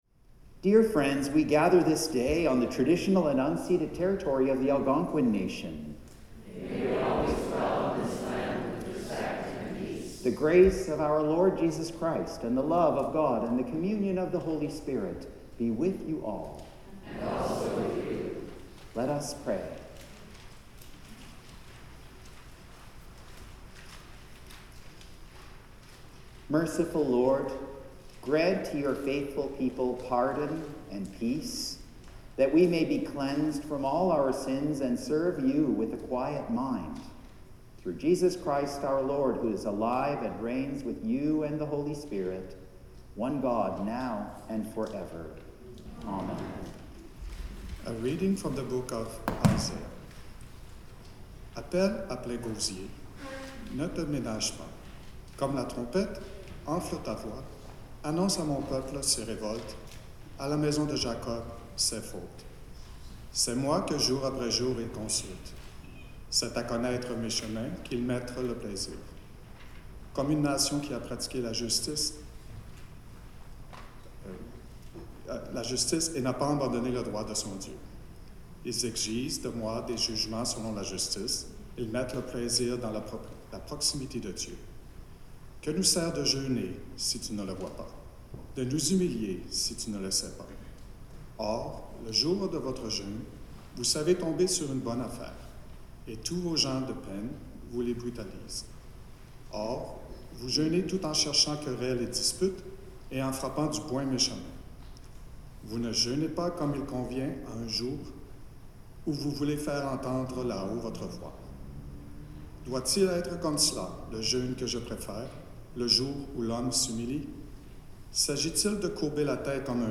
Greeting, Land Acknowledgment & Collect of the Day
(reading in French)
Hymn 505
Sermon
Anthem
soloist Doxology & Blessing Hymn 350